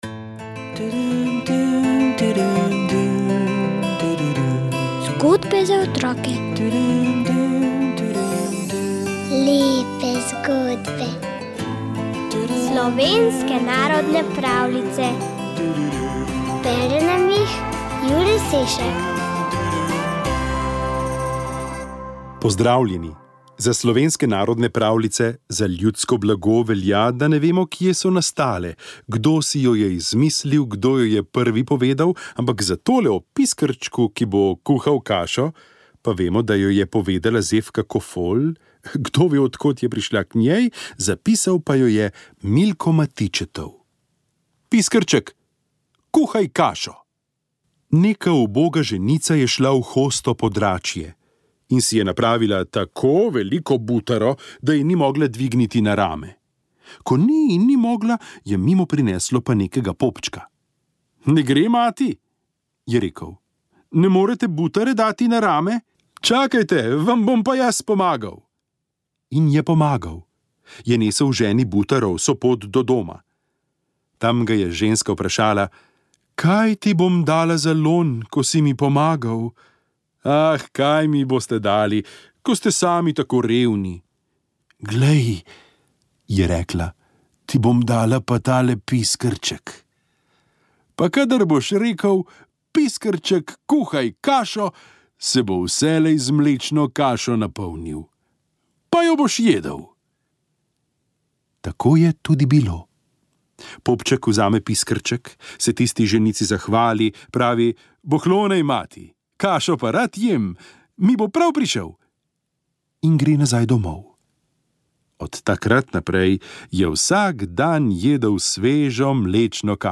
pravljica